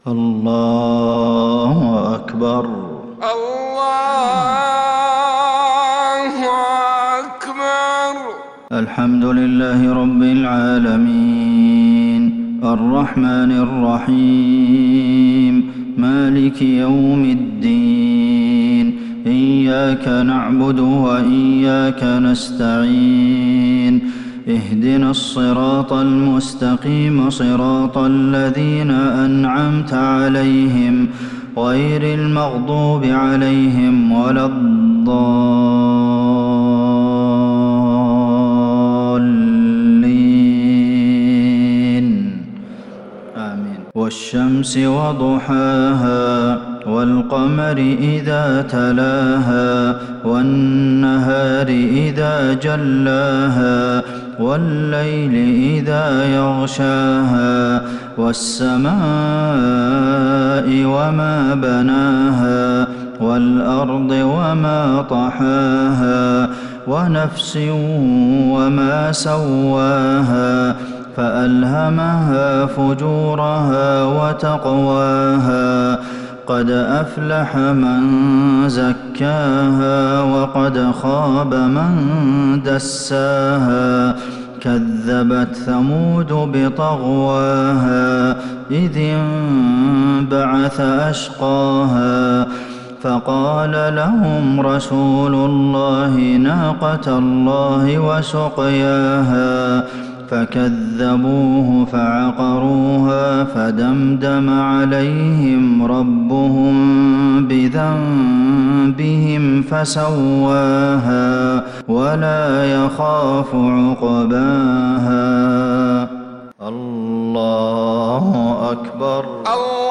صلاة المغرب للشيخ عبدالمحسن القاسم 9 شوال 1441 هـ
تِلَاوَات الْحَرَمَيْن .